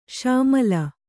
♪ śamala